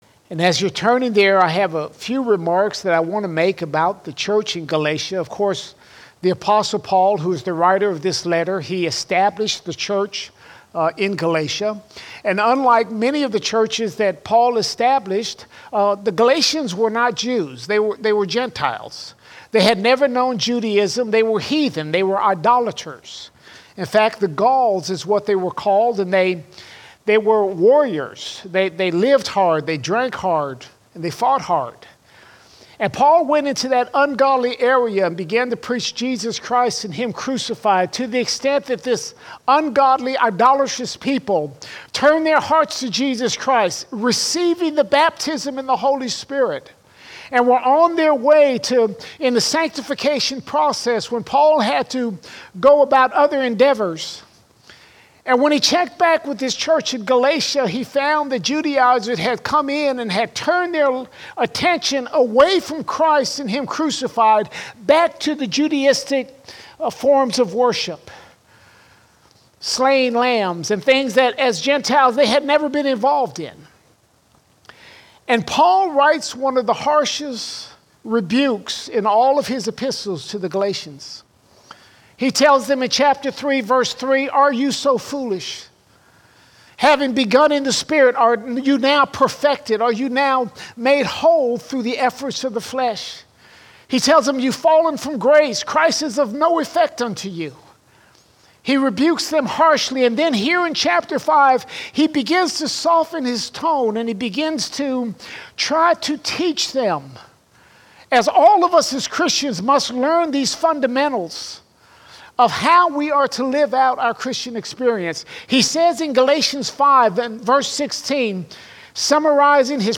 12 August 2024 Series: Sunday Sermons Topic: sin All Sermons Walk in the Spirit Walk in the Spirit How do you beat sin?